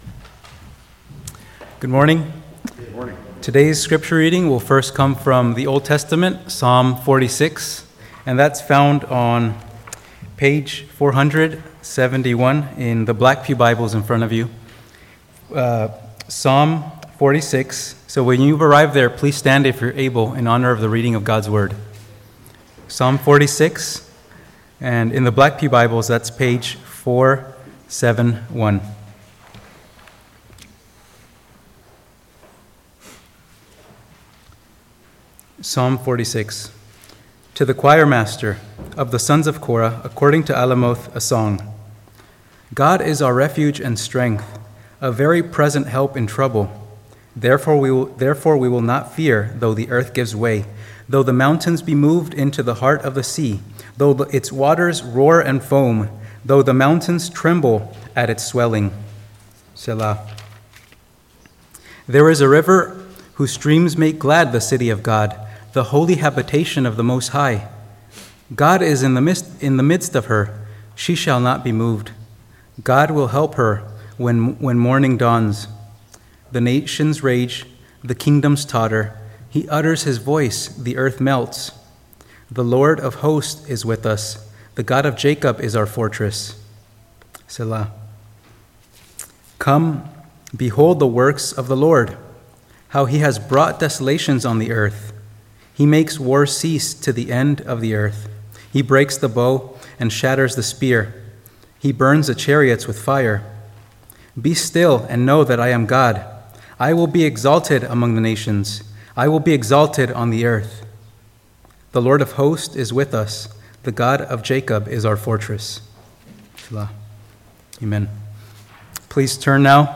Sermon: What’s The Big Deal?